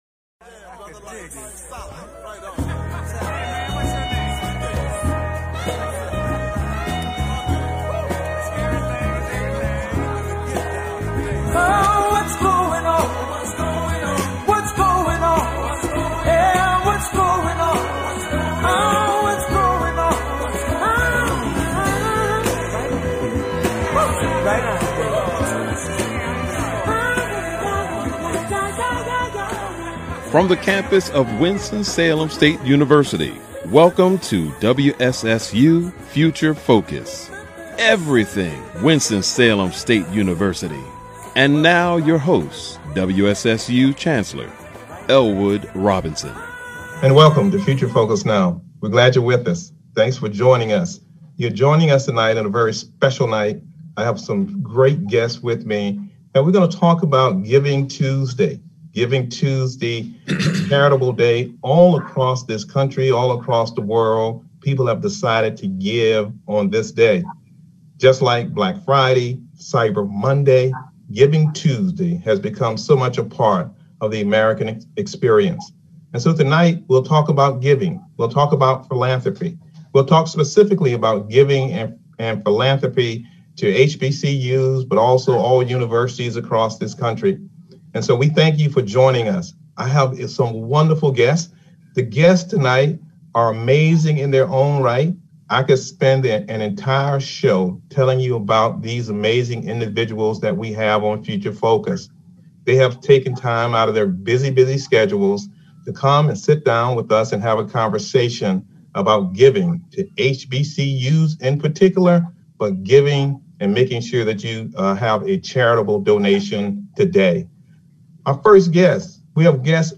Future Focus is a one-hour public affairs talk show hosted by Winston-Salem State University's Chancellor Elwood Robinson.